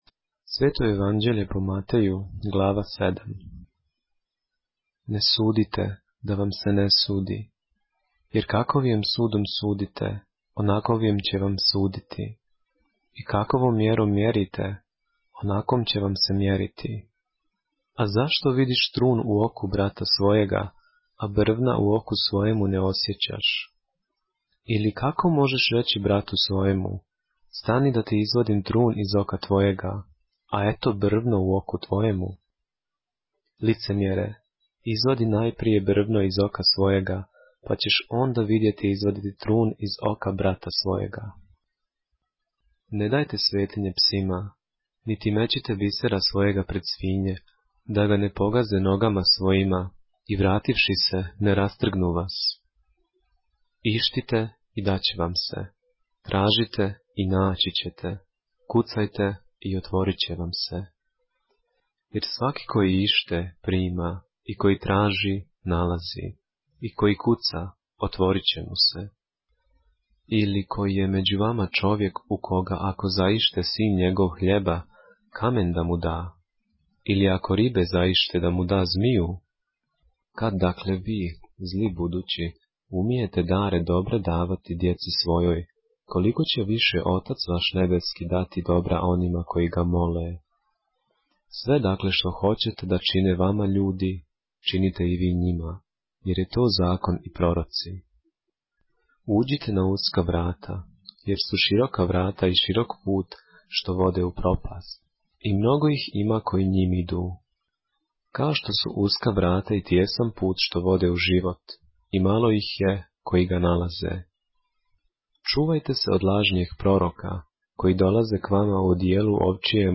поглавље српске Библије - са аудио нарације - Matthew, chapter 7 of the Holy Bible in the Serbian language